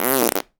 fart_squirt_14.wav